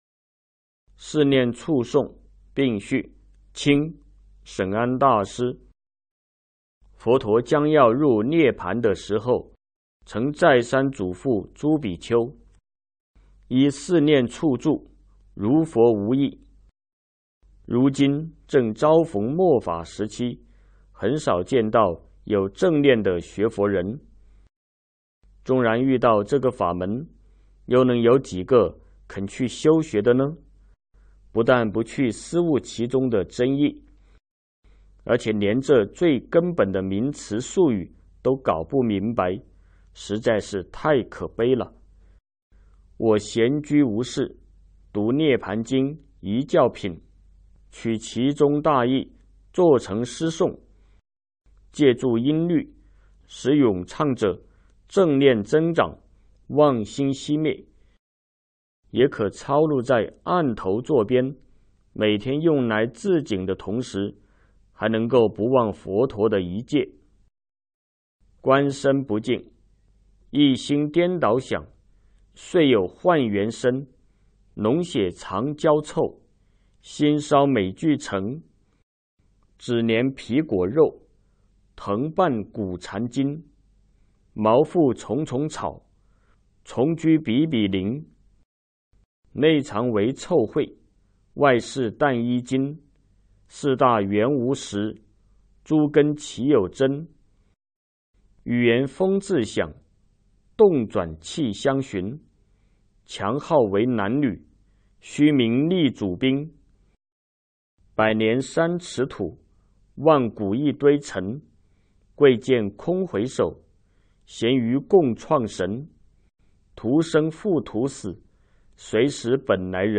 有声书